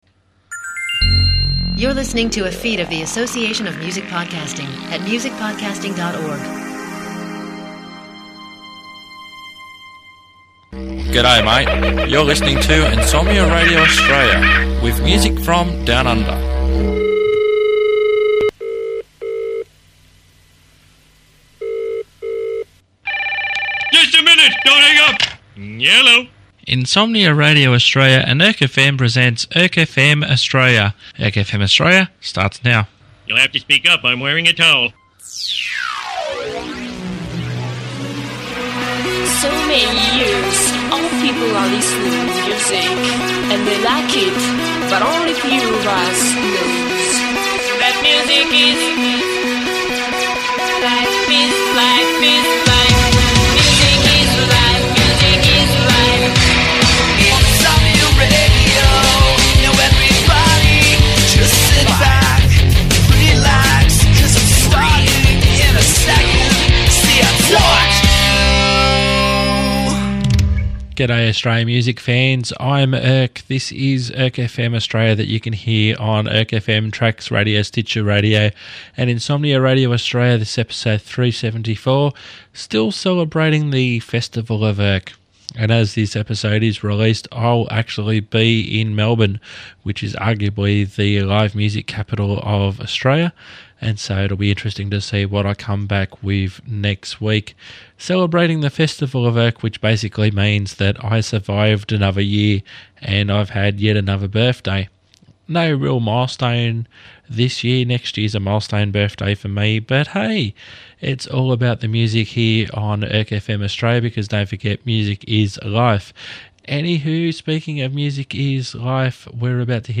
There's a couple of announcements & cracking tunes.